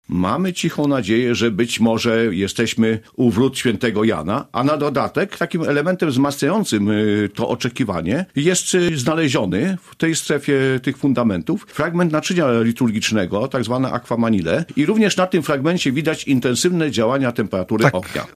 O tym, że odkrycie rzeczywiście jest pozostałością po kościele, świadczą znajdywane naczynia – dodaje archeolog.